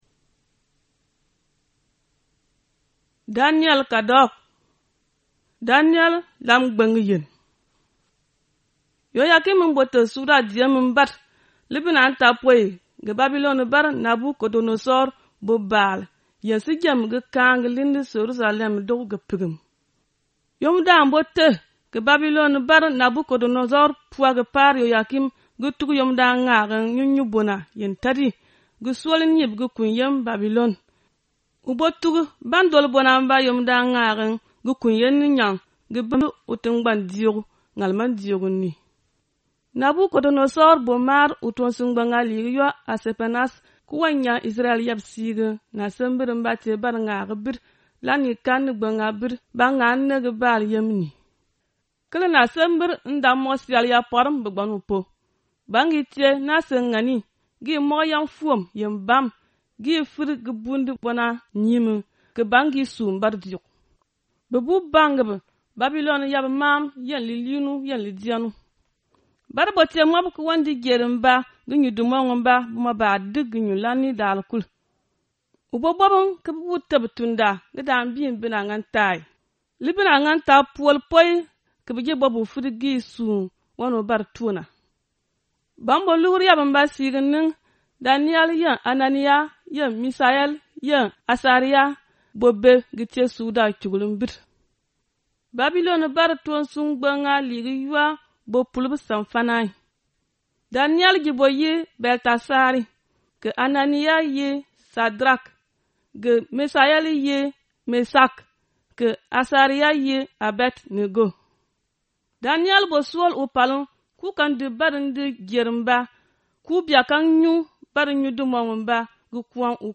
Vous pouvez écouter la lecture de l’Ancien Testament moba ici.